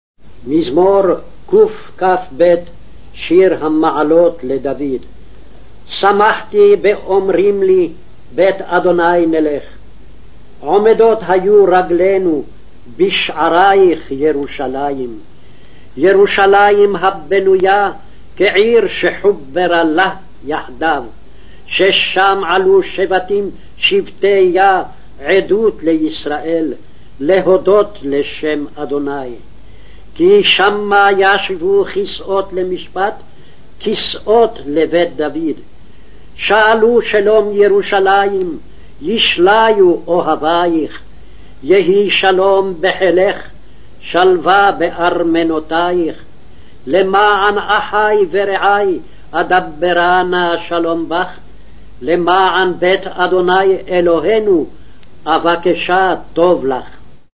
il testo della liturgia cattolica romana letta lentamente in ebraico